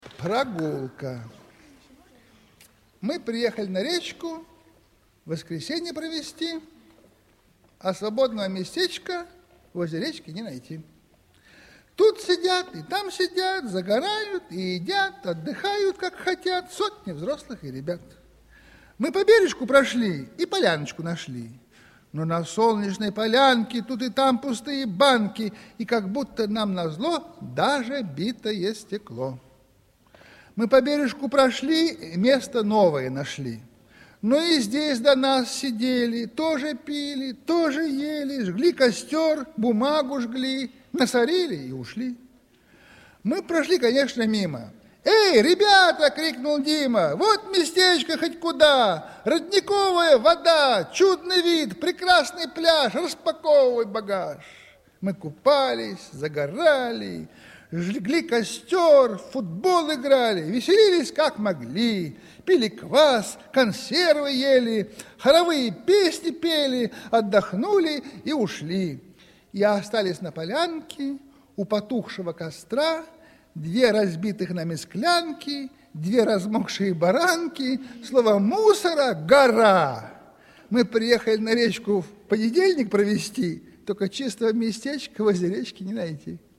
6. «Читай ухом здесь – Прогулка (Сергей Михалков) читает Сергей Михалков» /
uhom-zdes-Progulka-Sergey-Mihalkov-chitaet-Sergey-Mihalkov-stih-club-ru.mp3